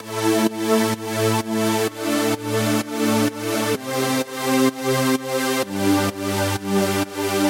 电音室领导
描述：只是一个有趣的合成器旋律的玩法
Tag: 130 bpm Electro Loops Synth Loops 636.10 KB wav Key : A